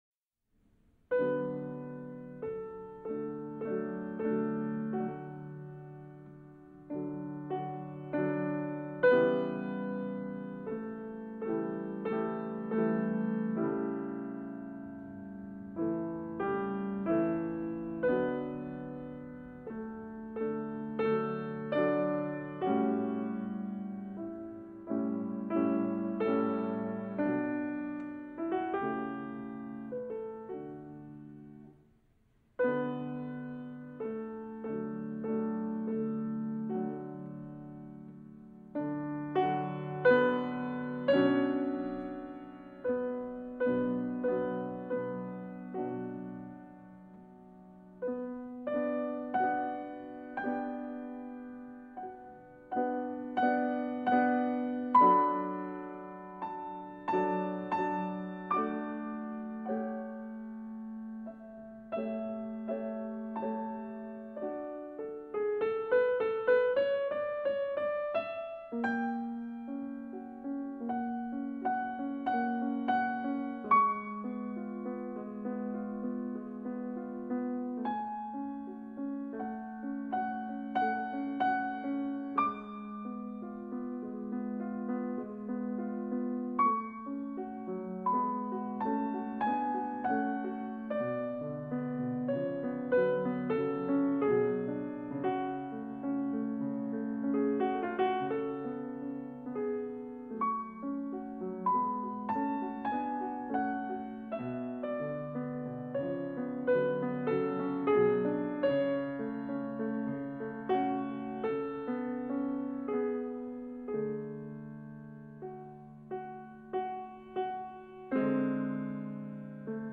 Schubert – Ambiente de piano para concentración lectora